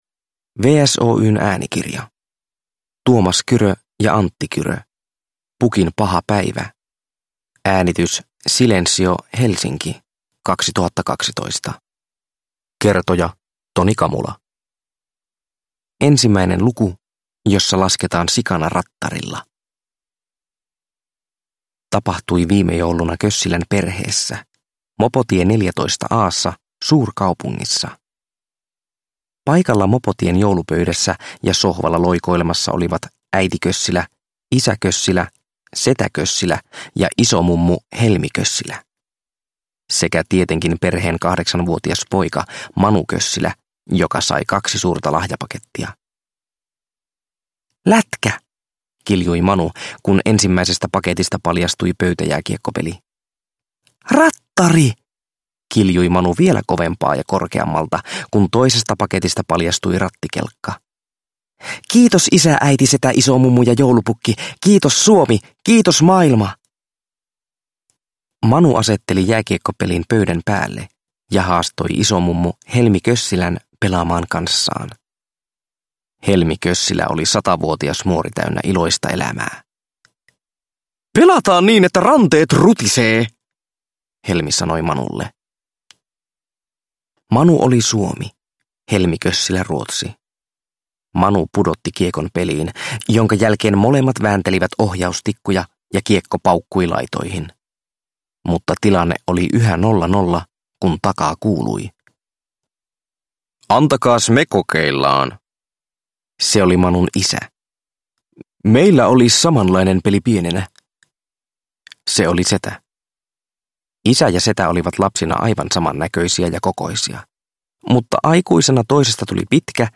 Lasten tuplaäänikirja, josta aikuinenkin innostuu!